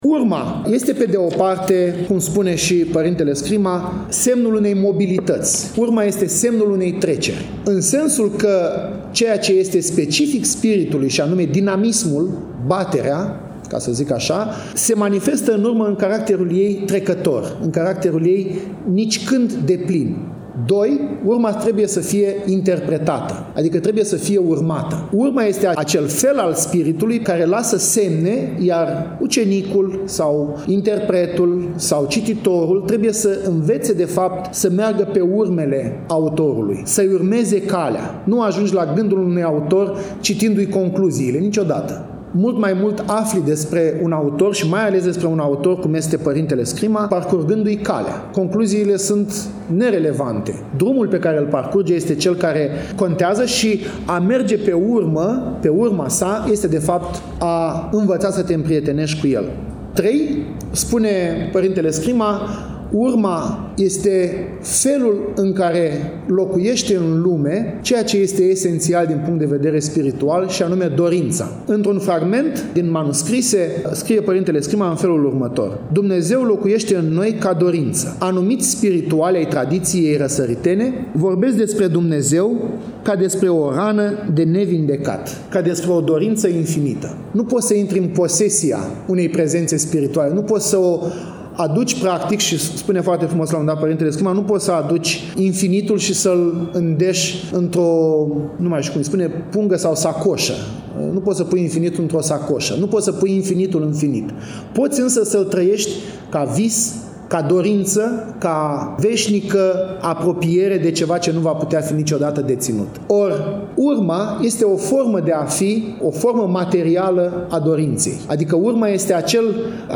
Conferința s-a desfășurat în ziua de vineri, 12 decembrie 2025, începând cu ora 13, în sala „B. P. Hasdeu”.